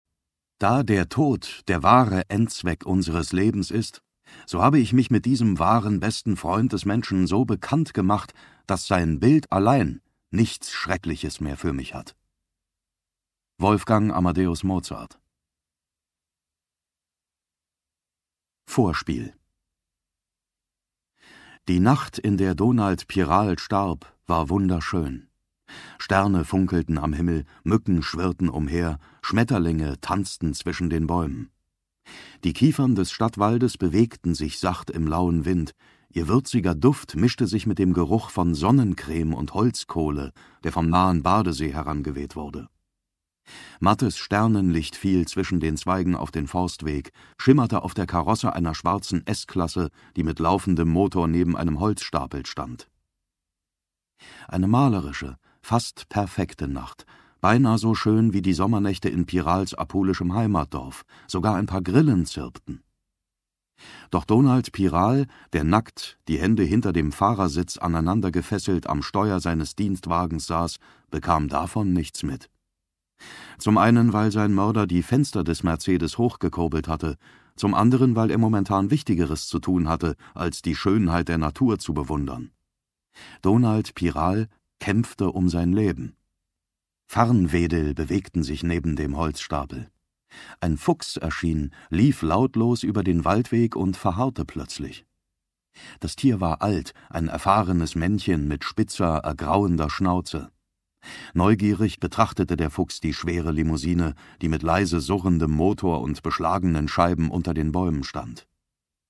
2019 | Ungekürzte Lesung
Mit seiner herausragenden Erzählkunst fesselt er den Hörer und zieht ihn tief in die Geschichte hinein.
Mitarbeit Sprecher: David Nathan